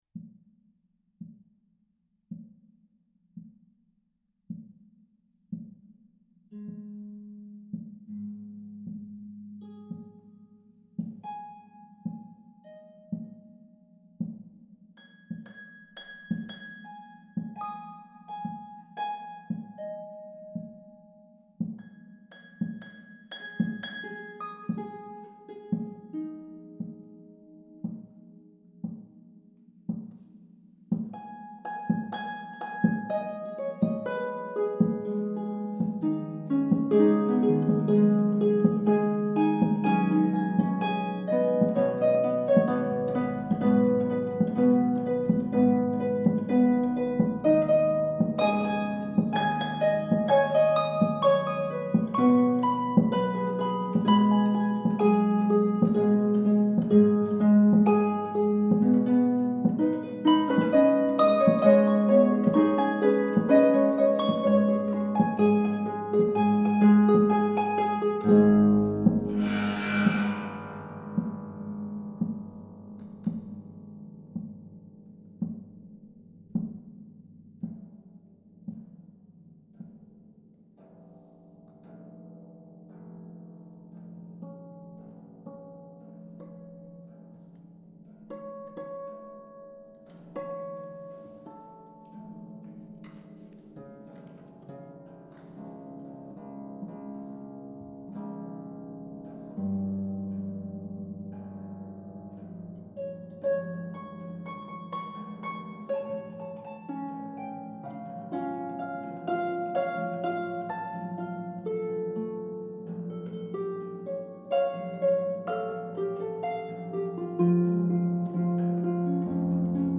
harps